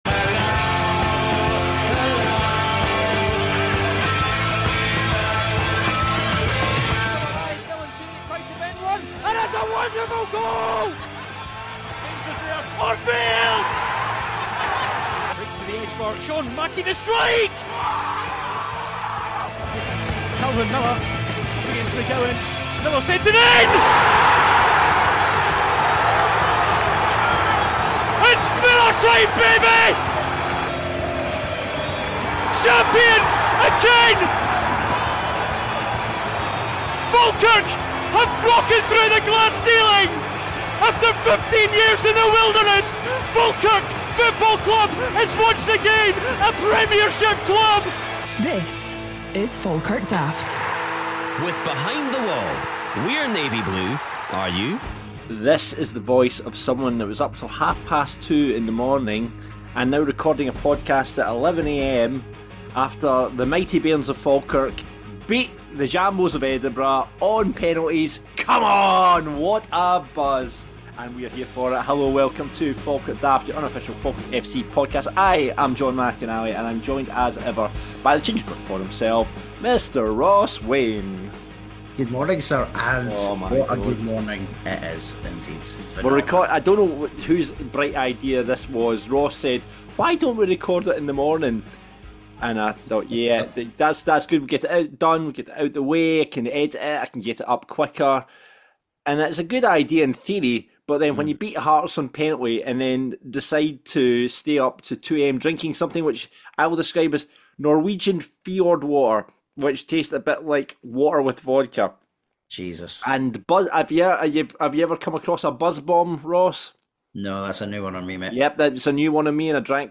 in the company of guest pundit